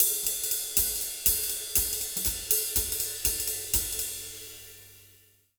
240SWING01-R.wav